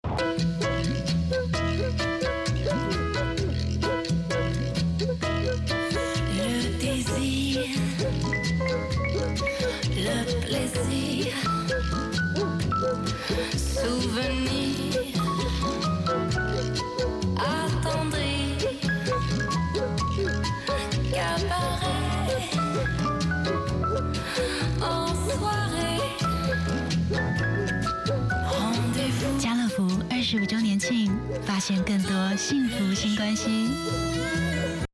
國語配音 女性配音員
✔ 聲音具有親和力，極具穿透力與辨識度